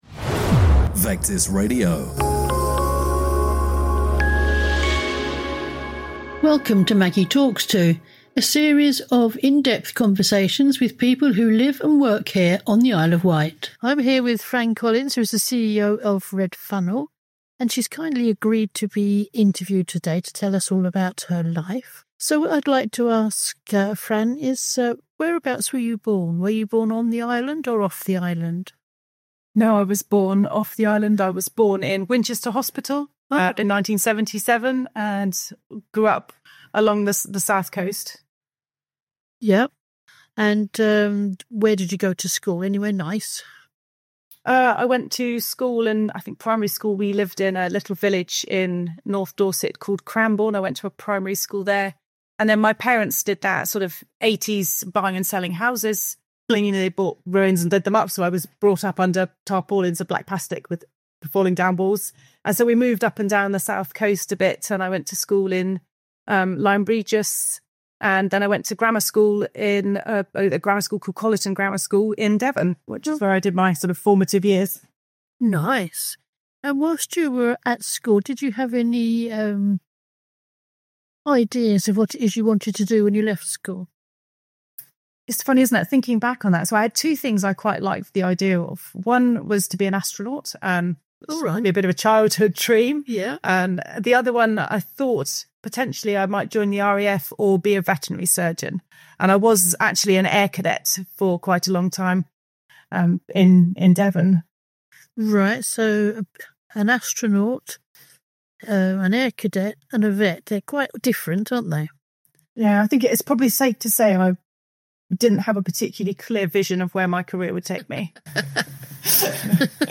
All our interviews from our incredible volunteering team, Catch up with guests that you may have missed on FM 104.6
Vectis Interviews 2026